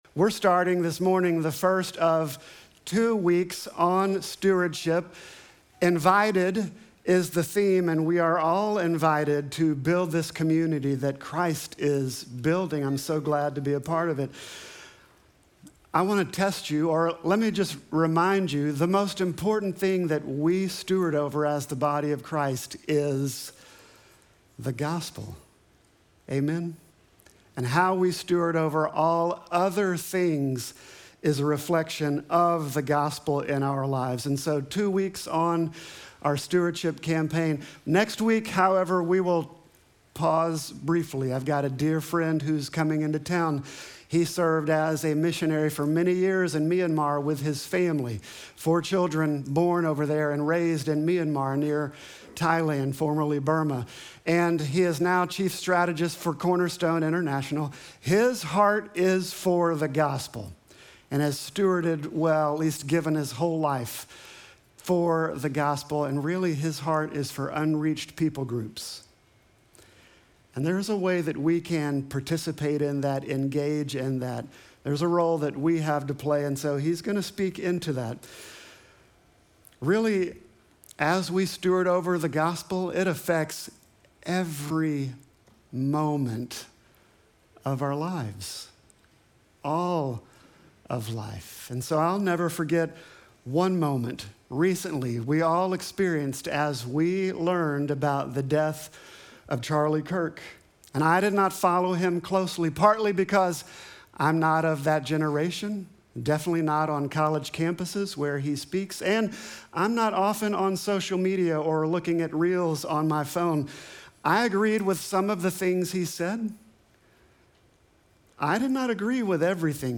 Sermon text: 2 Corinthians 8:7